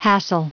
Prononciation du mot hassle en anglais (fichier audio)
Prononciation du mot : hassle